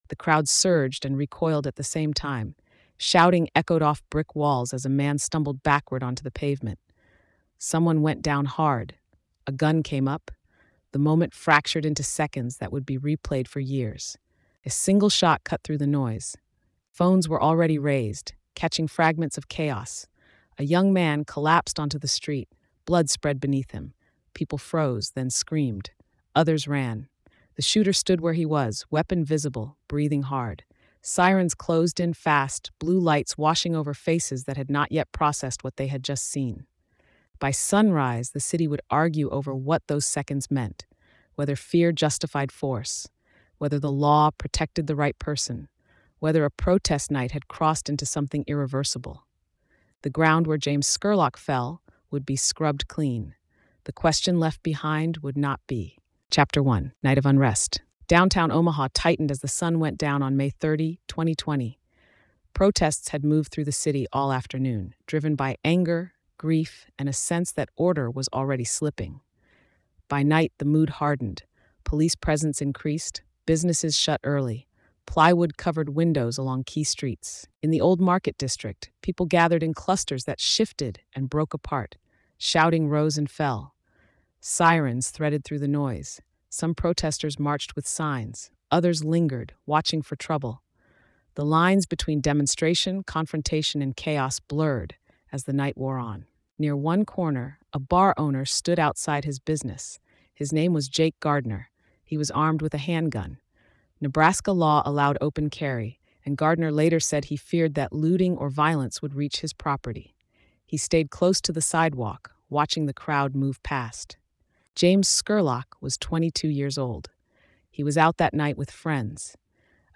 Told in a neutral, grounded voice, the narrative traces the incident from the chaotic street encounter through the investigation, legal decisions, and lasting civic fallout. The case became a dividing line for the city, raising unresolved questions about self defense, armed civilians, protest dynamics, and the gap between legal closure and public acceptance.